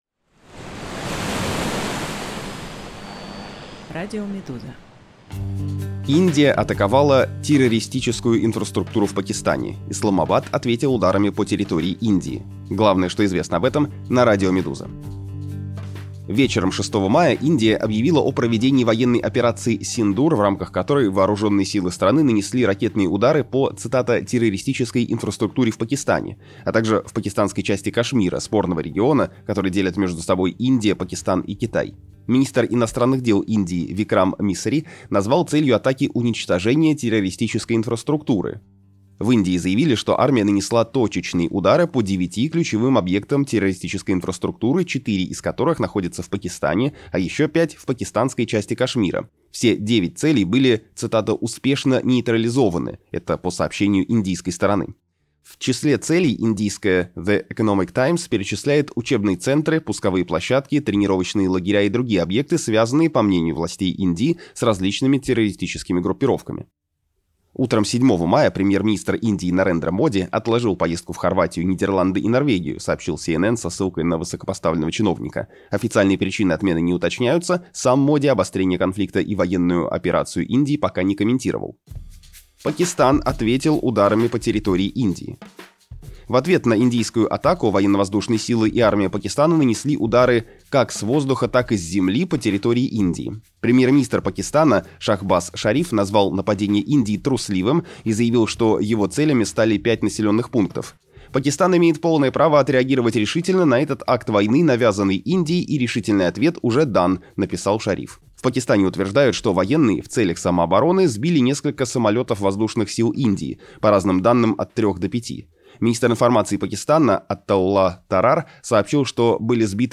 Аудиоверсии главных текстов «Медузы».